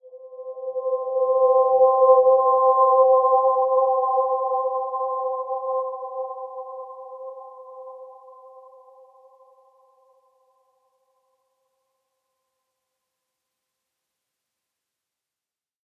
Dreamy-Fifths-C5-mf.wav